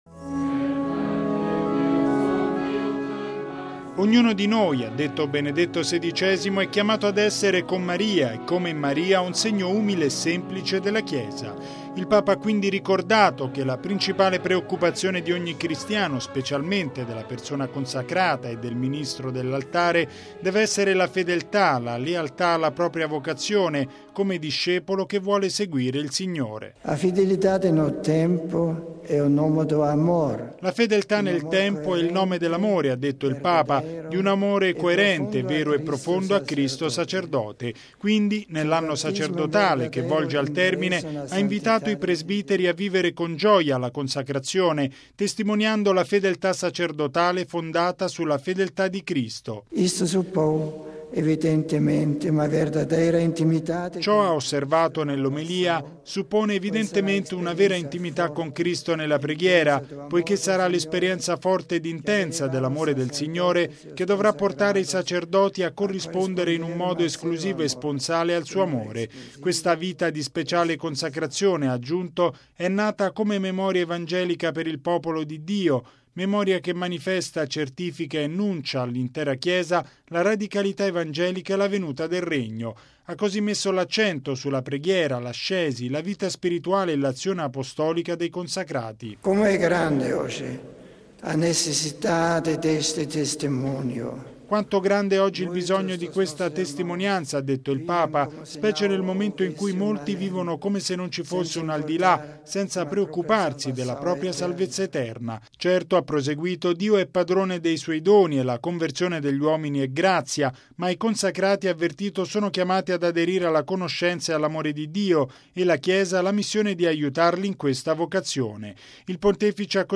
Con coraggio e fiducia, siate fedeli alla vostra vocazione: è l’esortazione di Benedetto XVI ai fedeli, in particolare ai consacrati, rivolta ieri sera alla celebrazione dei Vespri nella Chiesa della Santissima Trinità di Fatima, definita “ideale cenacolo di fede”.
Canti